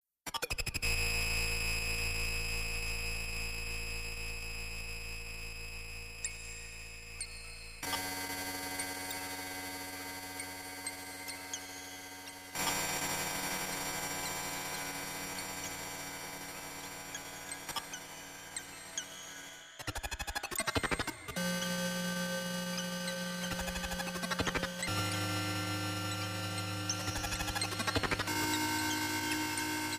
Electric Guitar and SuperCollider
Electronics